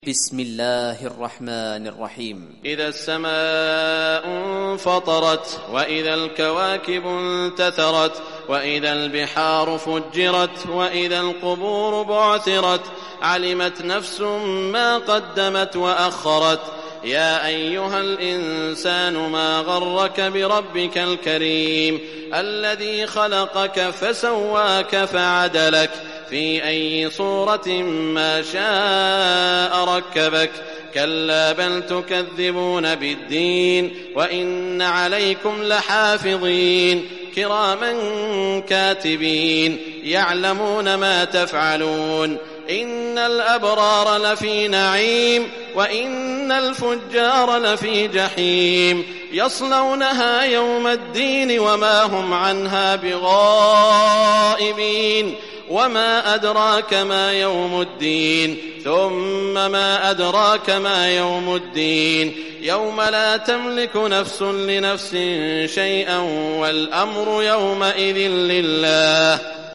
Surah Infitar Recitation by Sheikh Shuraim
Surah Infitar, listen or play online mp3 tilawat / recitation in Arabic in the beautiful voice of Sheikh Saud al Shuraim.